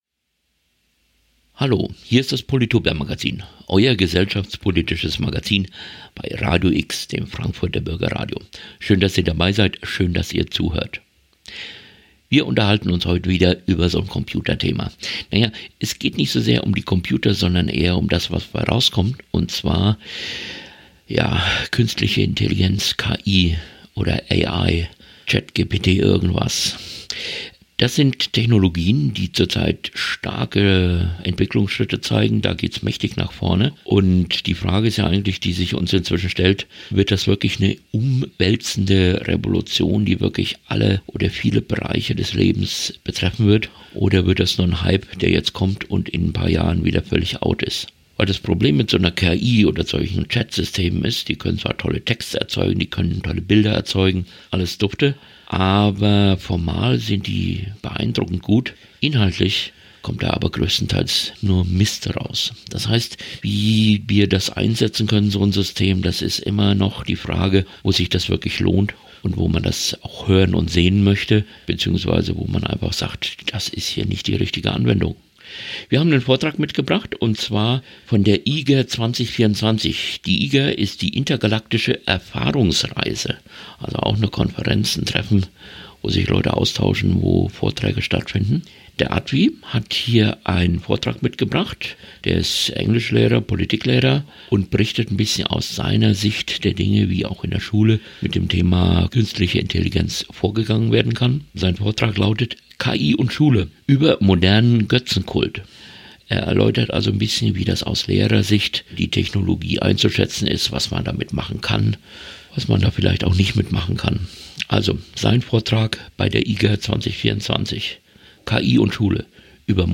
Wir hören einen Vortrag
bei der Intergalaktischen Erfahrungsreise 2024